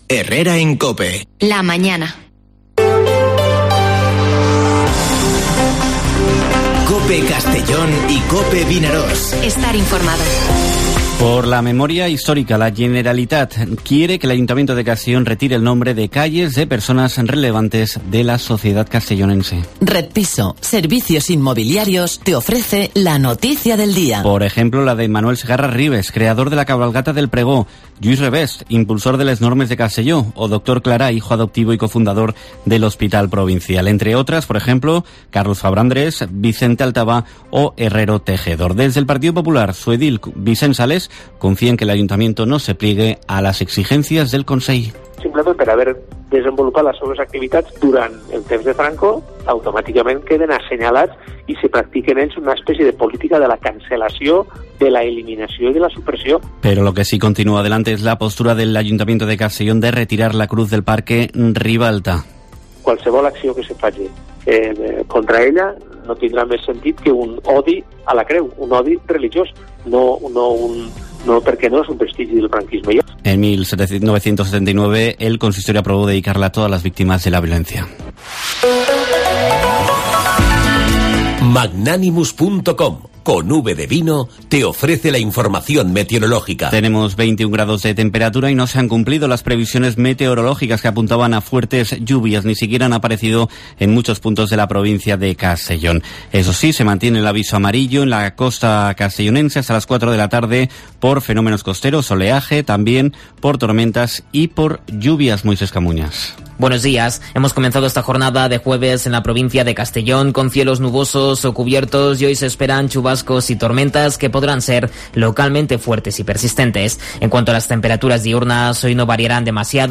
Informativo Herrera en COPE en la provincia de Castellón (23/09/2021)